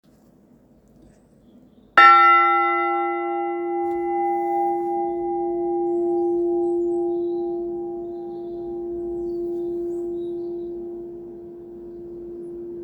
cloche n°4 - Inventaire Général du Patrimoine Culturel
Enregistrement sonore de la cloche n°4 par tintement manuel (octobre 2024).